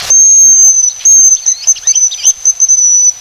Gélinotte des bois
Bonasia bonasia
gelinotte.mp3